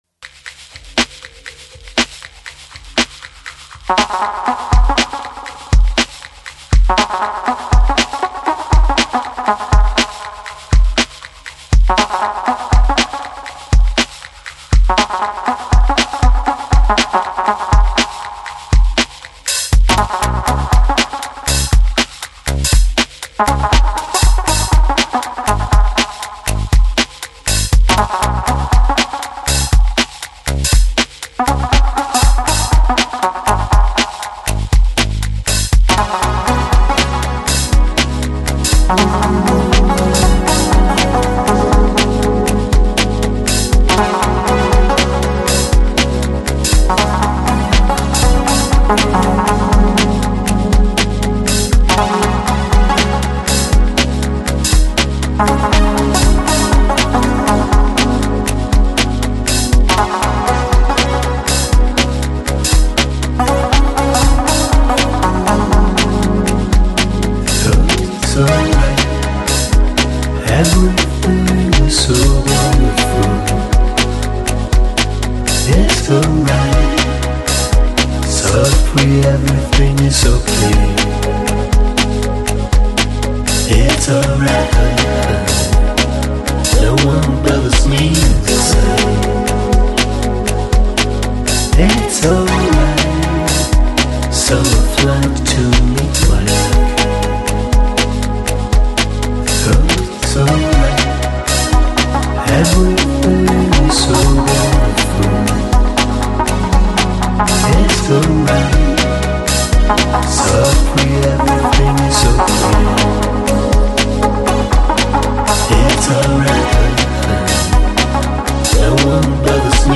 Жанр: Downtempo, Lounge, Chillout